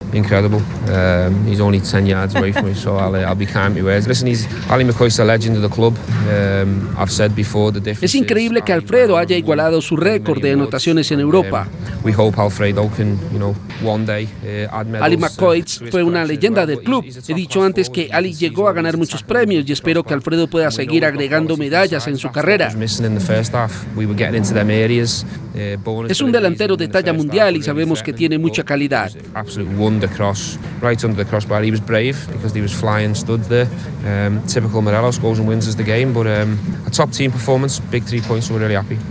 (Steven Gerrard, DT del Rangers)
«Ally es una leyenda del club», explicó en rueda de prensa Steven Gerrard, entrenador del Rangers.